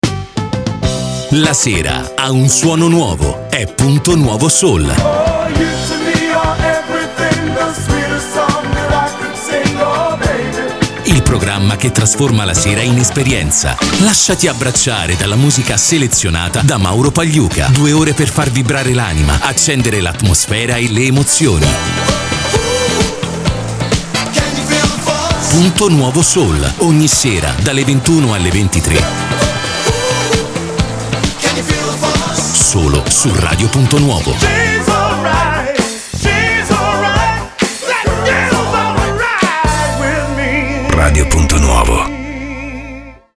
ASCOLTA IL PROMO